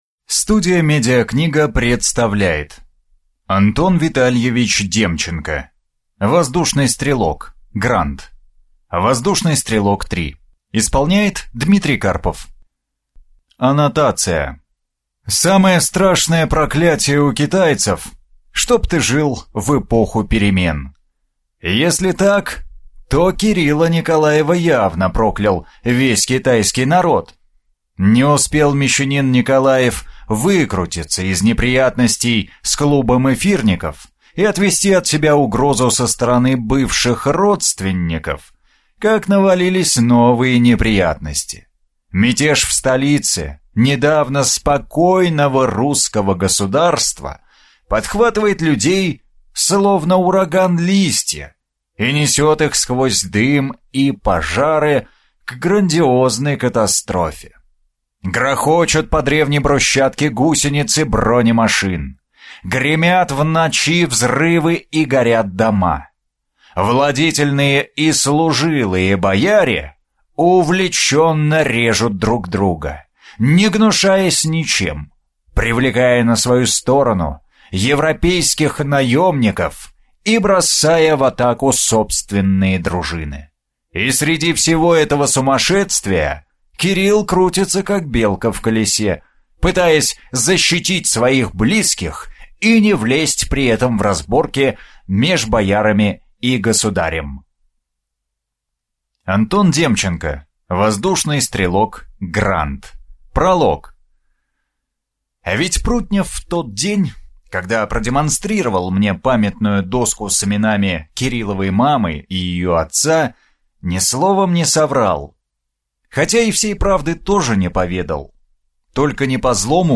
Аудиокнига Воздушный стрелок. Гранд | Библиотека аудиокниг